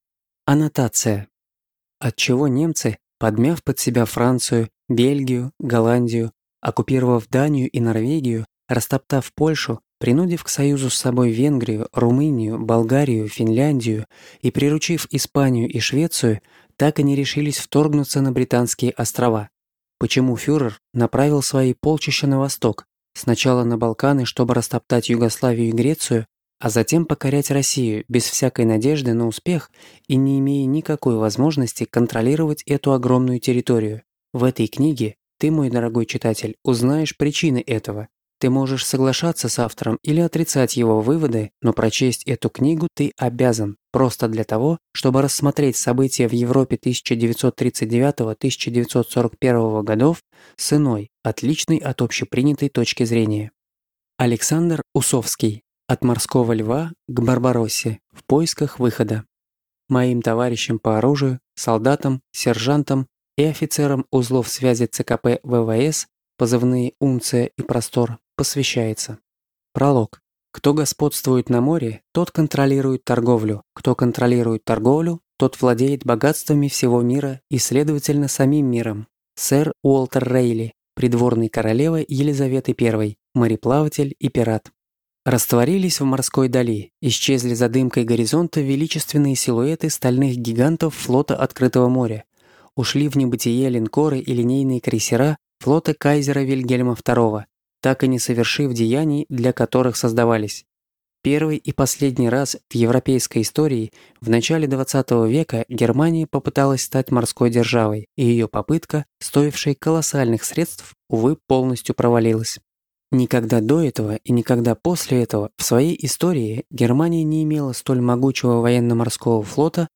Аудиокнига От «Морского Льва» к «Барбароссе»: в поисках выхода | Библиотека аудиокниг